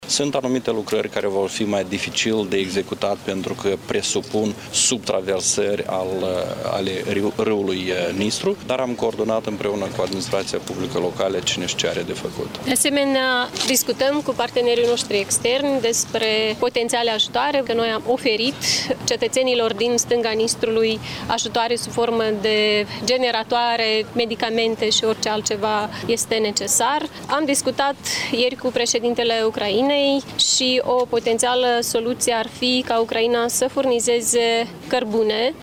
Maia Sandu: O potențială soluție ar fi ca Ucraina să furnizeze cărbune